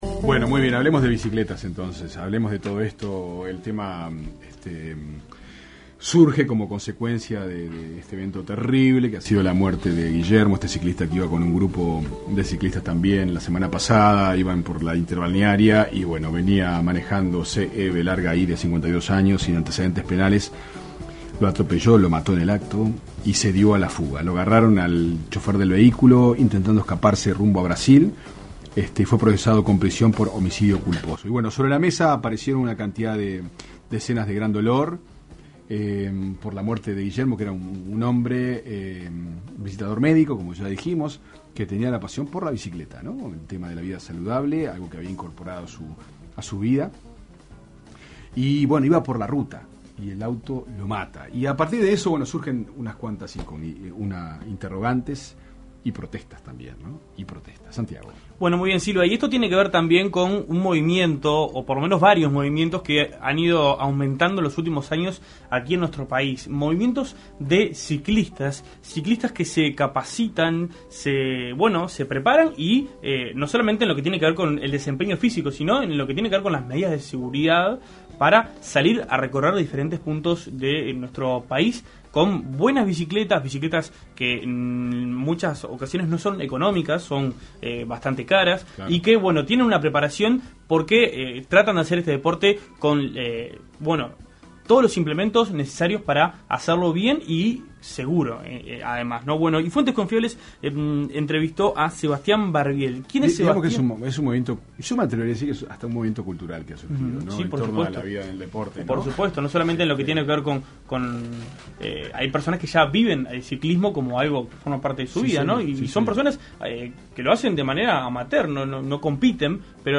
Escuche el informe de Fuentes Confiables: